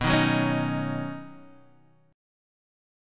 Badd9 chord